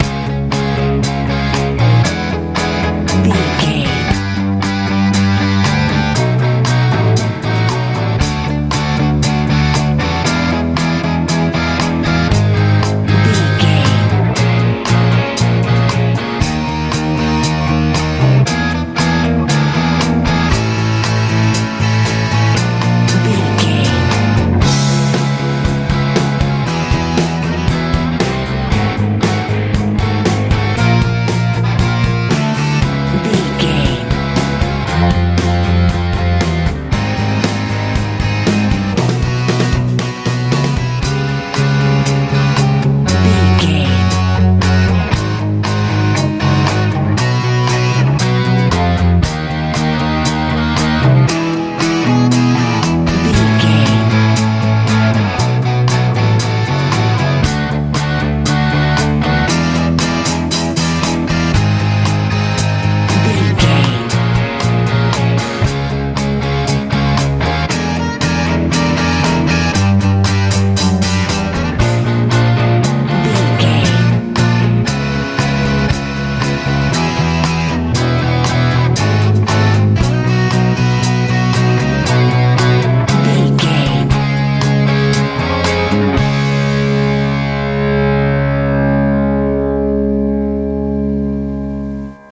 med rock feel
Ionian/Major
C♯
driving
energetic
electric guitar
bass guitar
drums
80s
90s